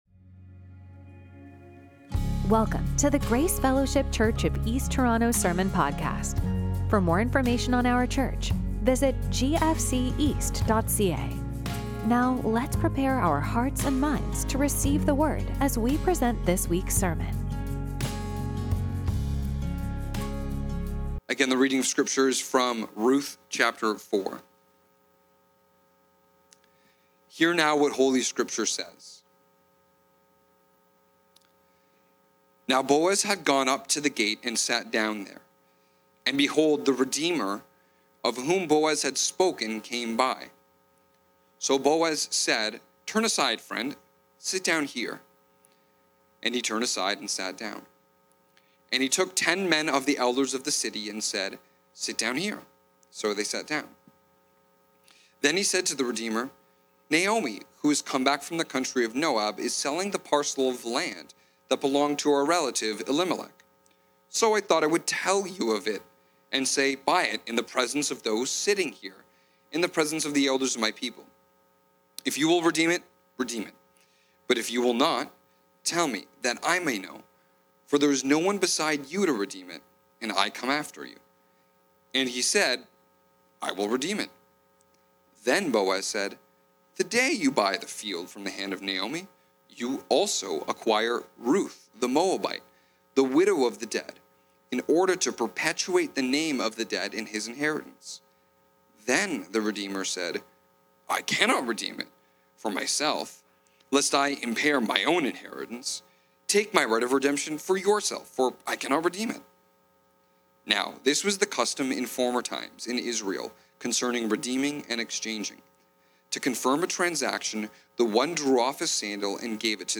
Sermons from Grace Fellowship Church East Toronto